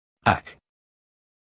Phoneme_(Umshk)_(Aak)_(Male).mp3